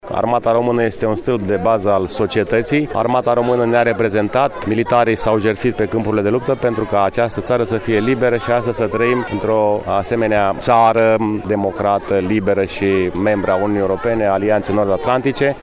Un ceremonial militar și religios a avut loc la Cimitirul Șprenghi, unde, după alocuțiunile oficiale, au fost depuse coroane de flori.